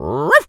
dog_small_bark_05.wav